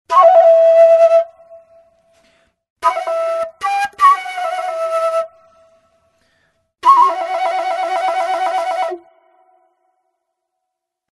Зов природы в звуках флейты